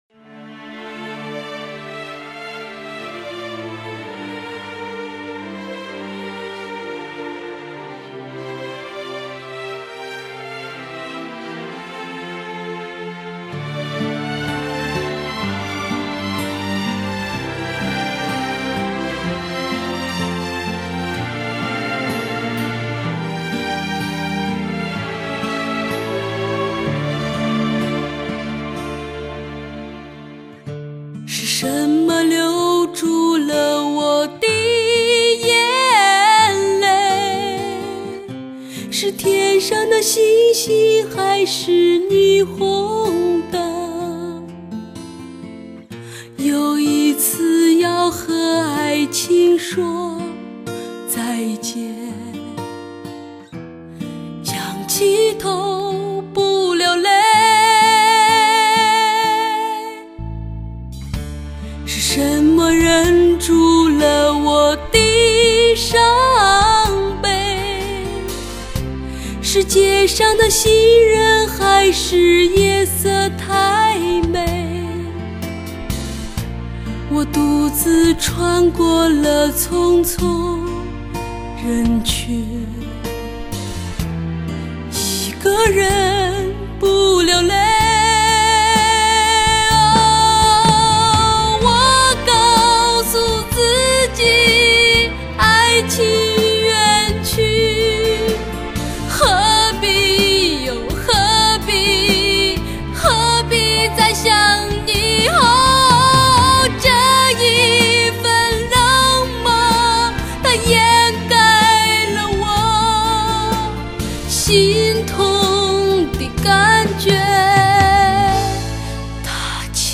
流畅，悲凄却蕴含豪情，鼓舞人们勇敢地去面对生活，搏击人生。